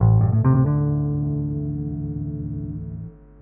描述：电贝司
Tag: 贝司